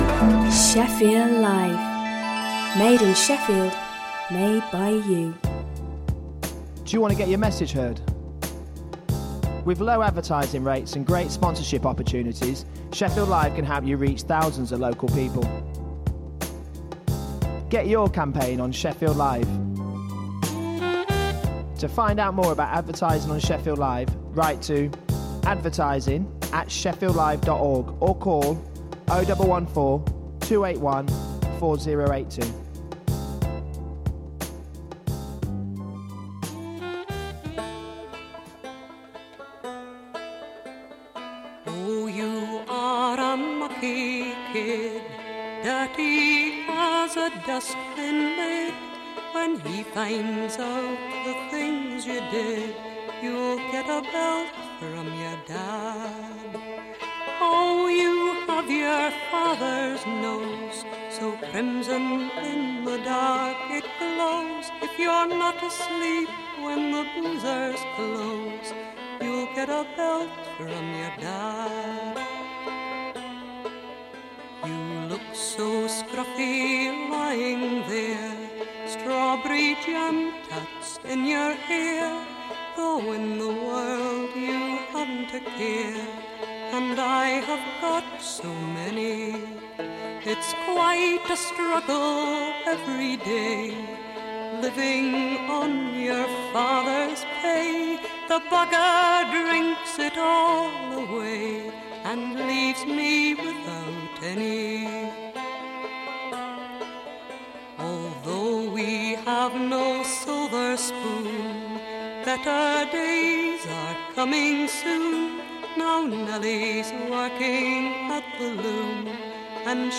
Jolly music, news, chat and regular prize giveaways!